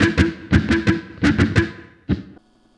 Track 02 - Guitar Lead 07.wav